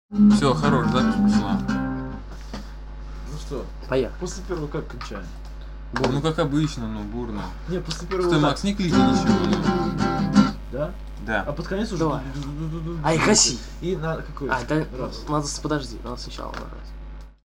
Все песни были записаны и сведены мной в домашних условиях.